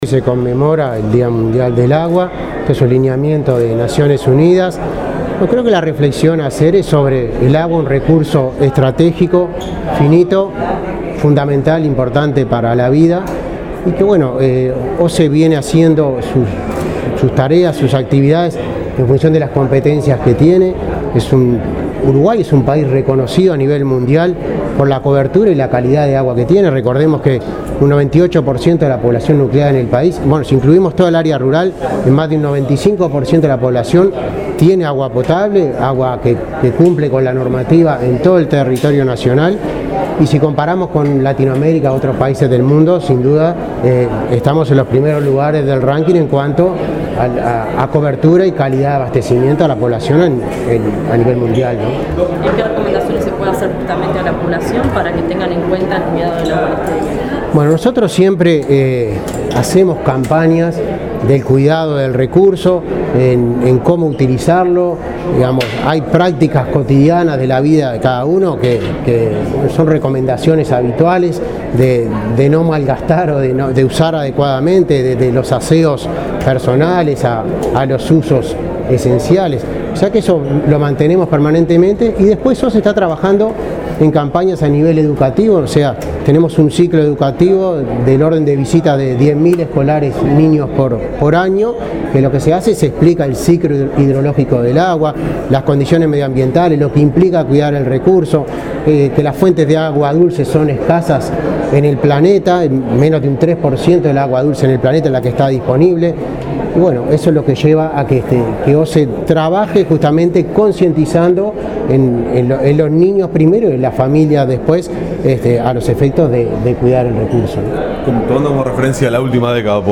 Uruguay es reconocido a nivel mundial por la cobertura y la calidad de su agua, subrayó el presidente de OSE, Milton Machado, quien recordó que más de un 95% de la población tiene agua potable. En el Día Mundial del Agua, recordó que el ente ha desarrollado nuevas infraestructuras desde mejoras en plantas potabilizadoras hasta gestión de laboratorios que realizan 50 mil análisis por año para monitorear la calidad del agua.